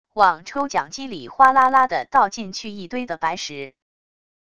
往抽奖机里哗啦啦地倒进去一堆的白石wav音频